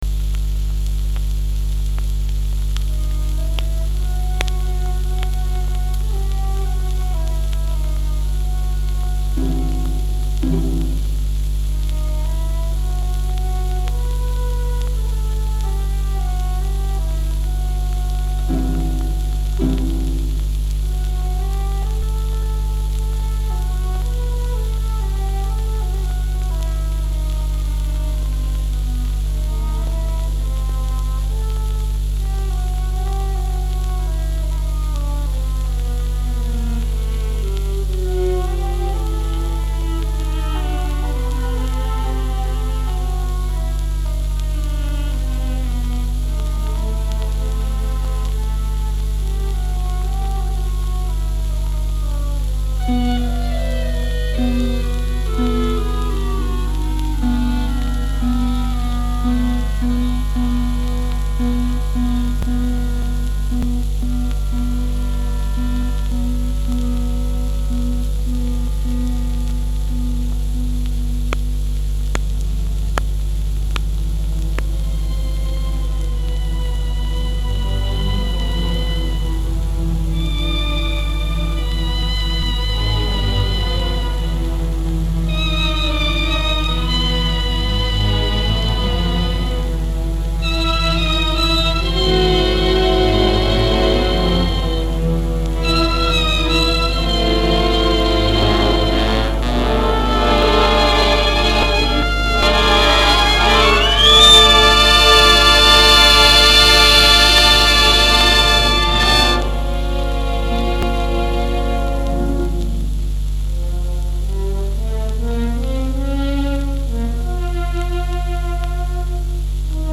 Описание: Эпическое симфоническое произведение.